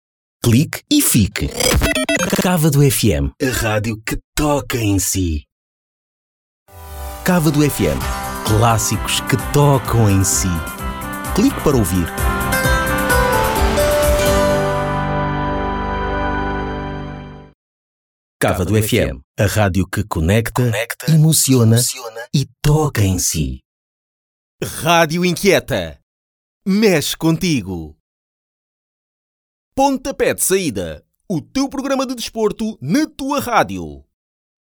Radio Imaging
Also, I can do many voice tones: calm, energetic, friendly, aggressive, informative, funny, and many more.
If you want a versatile Portuguese male voice, contact me.
Microphone: Rode NT-1A large diaphragm